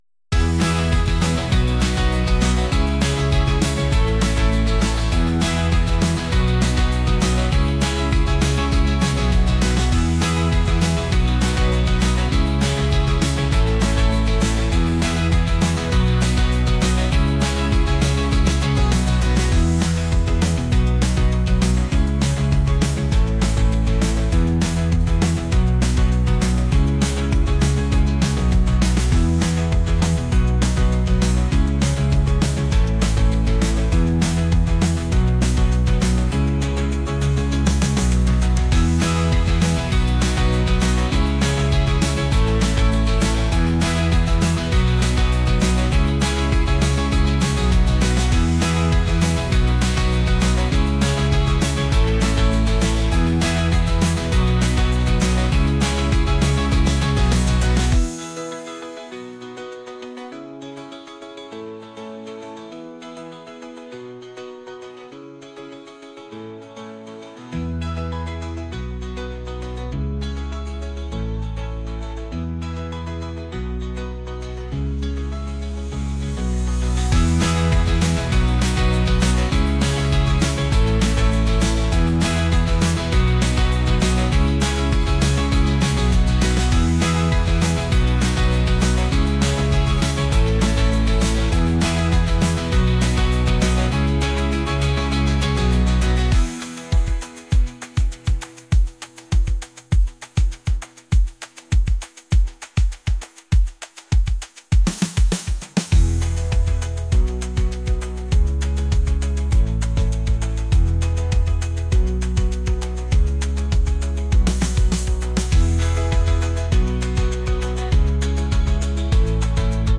pop | indie